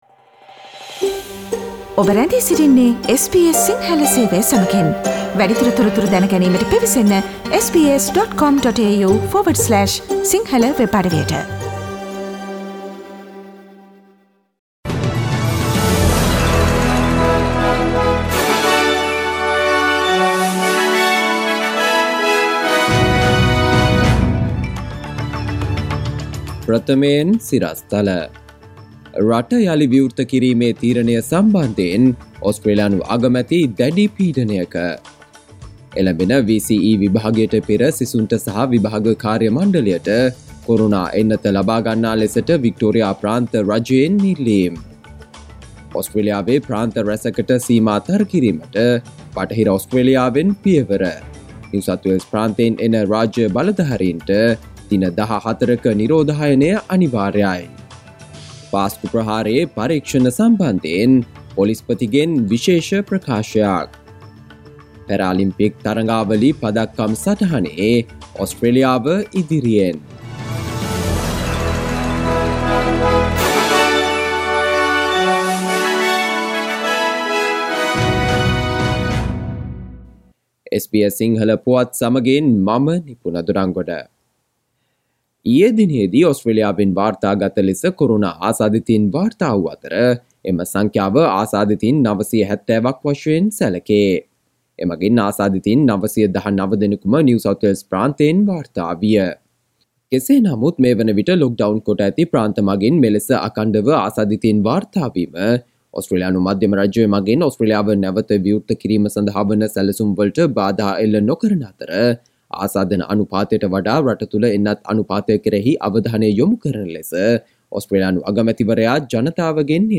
සවන්දෙන්න 2021 අගෝස්තු 26 වන බ්‍රහස්පතින්දා SBS සිංහල ගුවන්විදුලියේ ප්‍රවෘත්ති ප්‍රකාශයට...